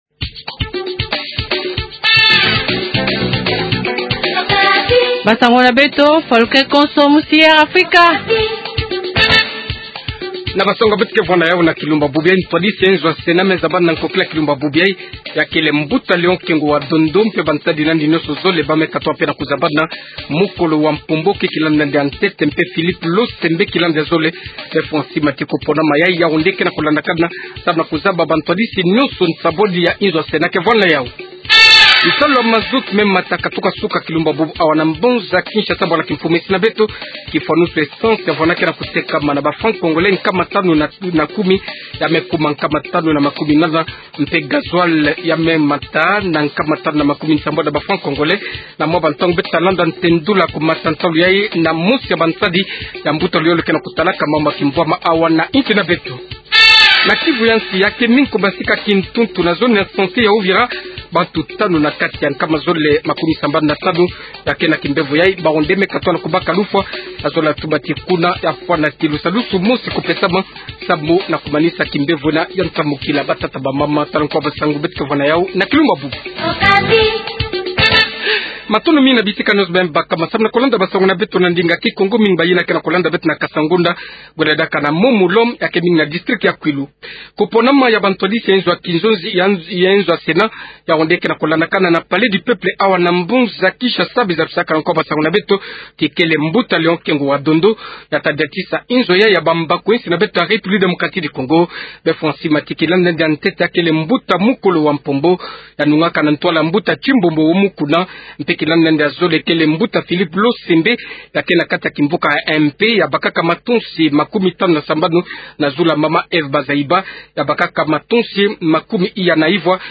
110507-journal kikongo soir